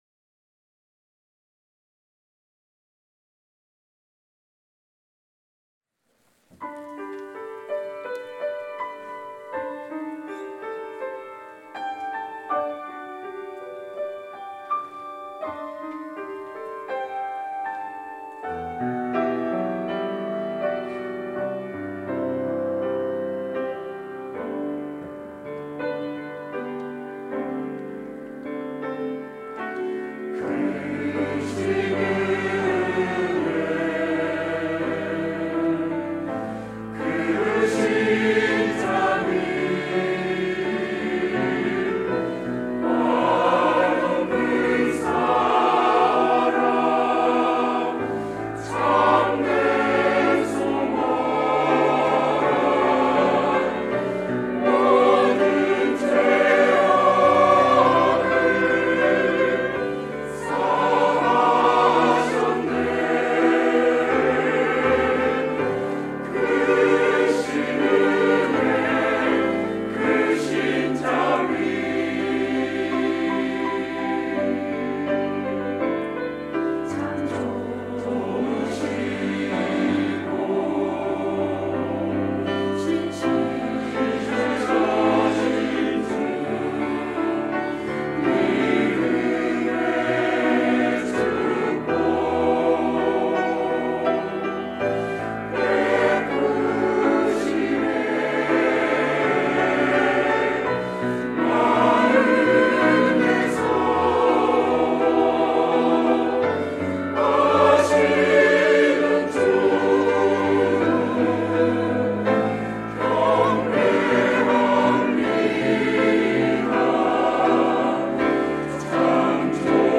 임마누엘